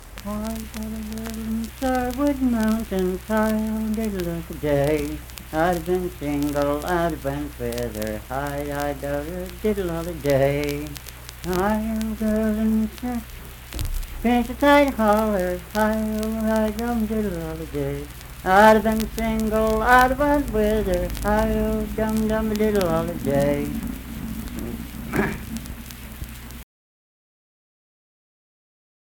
Unaccompanied vocal music performance
Verse-refrain 2(4w/R).
Dance, Game, and Party Songs
Voice (sung)